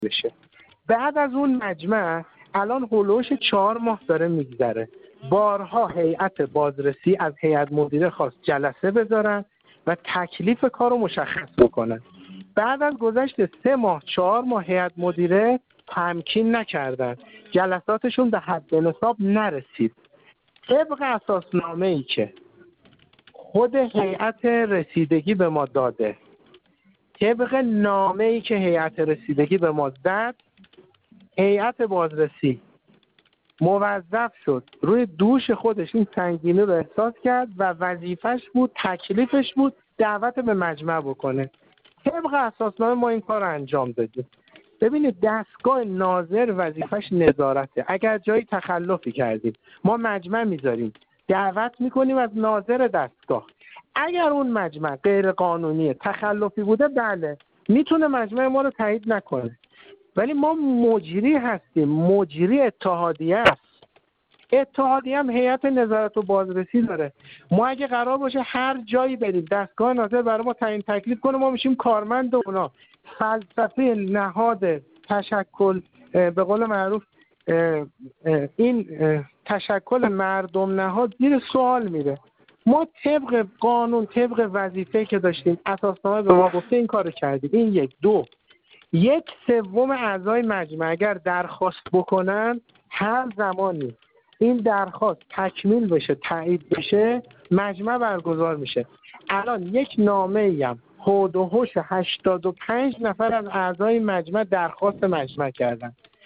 در گفت‌وگو با ایکنا